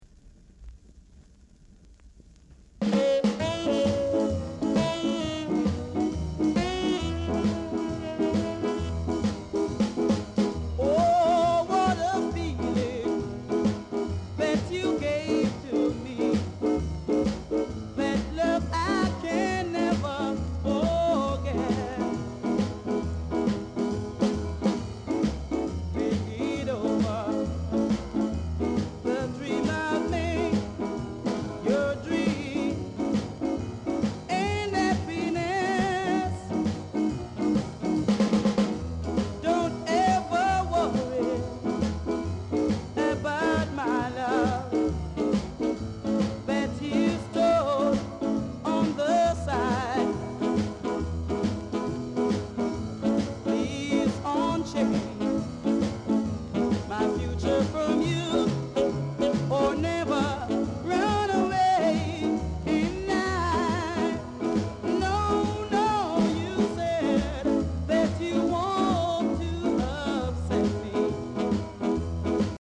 Ska Male Vocal